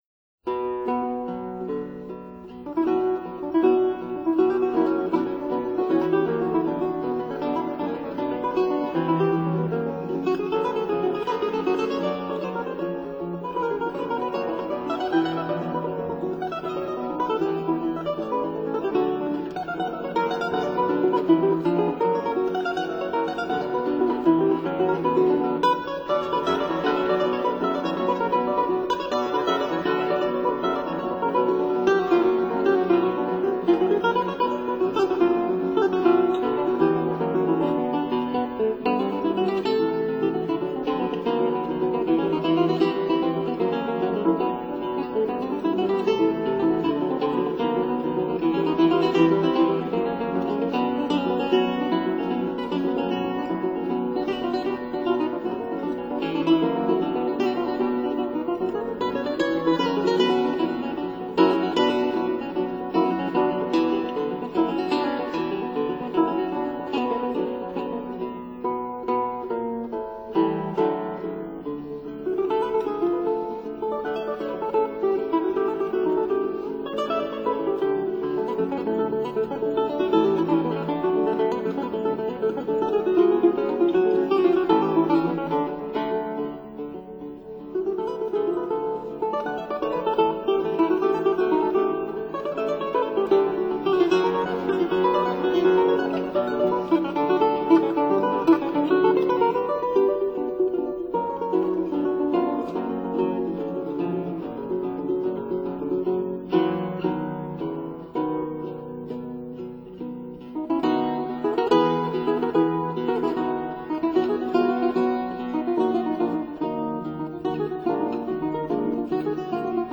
Duos for Two Guitars, Op. 241